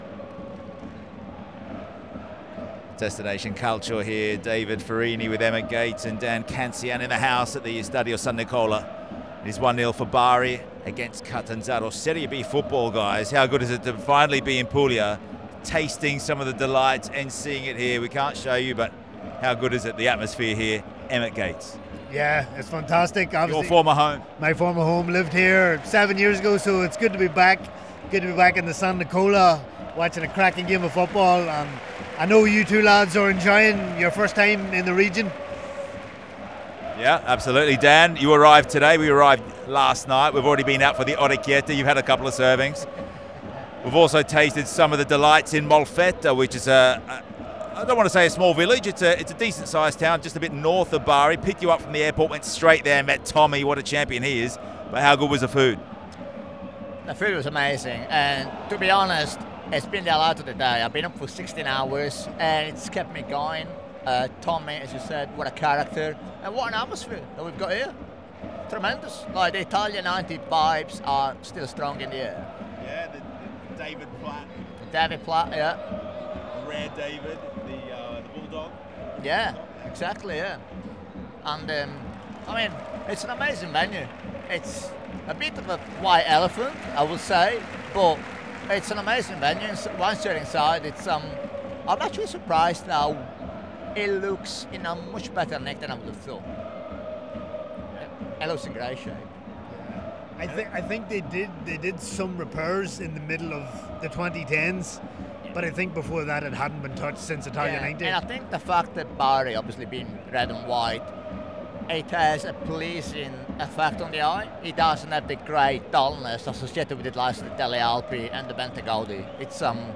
Bari: Live at the Stadio San Nicola